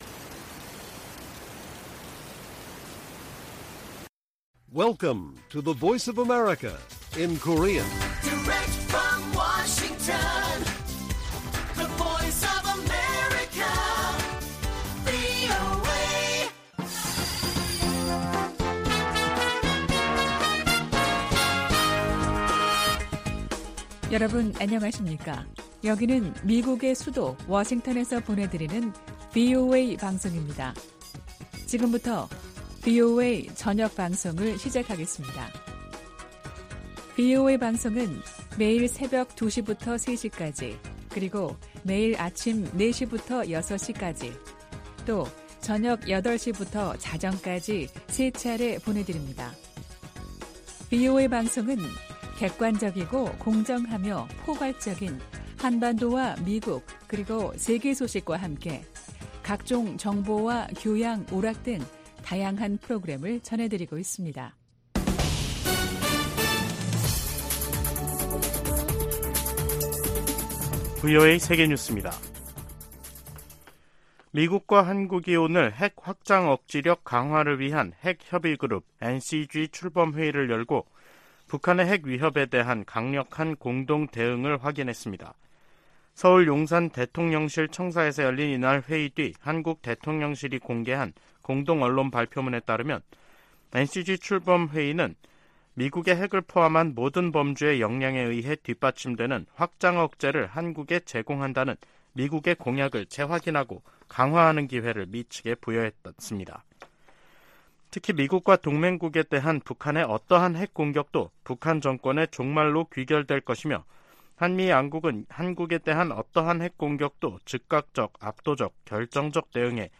VOA 한국어 간판 뉴스 프로그램 '뉴스 투데이', 2023년 7월 18일 1부 방송입니다. 미국과 한국은 18일 서울에서 핵협의그룹(NCG) 첫 회의를 갖고 북한이 핵 공격을 할 경우 북한 정권은 종말을 맞을 것이라며, 확장억제 강화의지를 재확인했습니다. 미 국무부는 북한의 도발에 대한 유엔 안보리의 단합된 대응을 촉구했습니다. 아세안지역안보포럼(ARF)이 의장성명을 내고, 급증하는 북한의 탄도미사일 발사가 역내 평화를 위협한다고 비판했습니다.